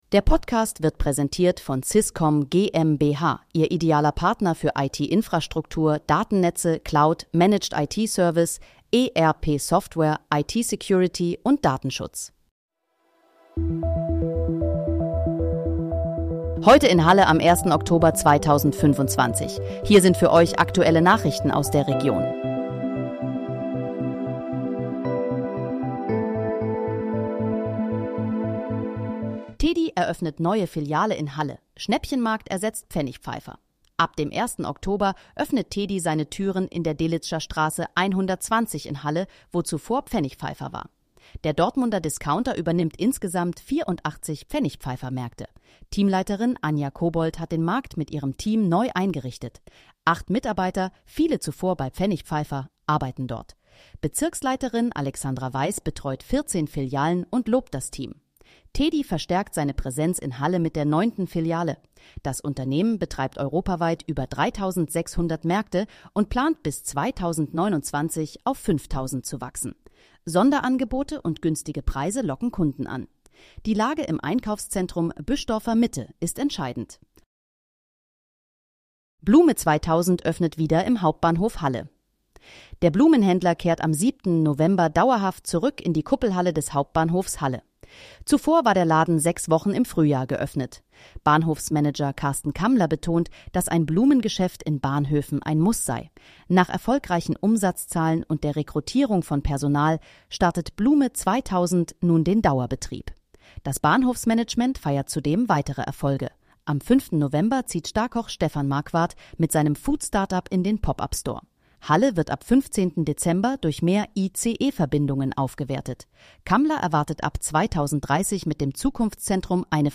Heute in, Halle: Aktuelle Nachrichten vom 01.10.2025, erstellt mit KI-Unterstützung
Nachrichten